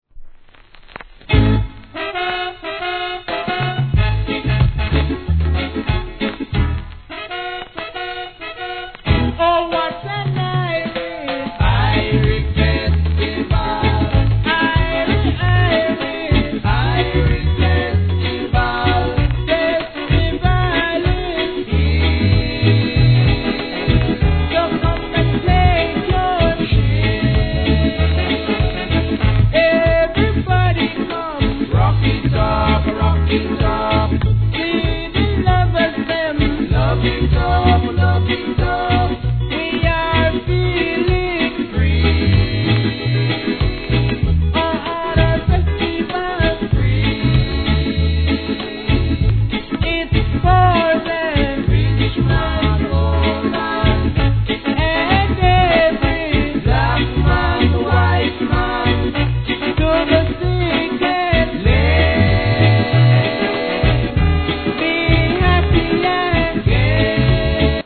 REGGAE
タイトル通りの気持ちいいFESTIVALチュ〜ン!!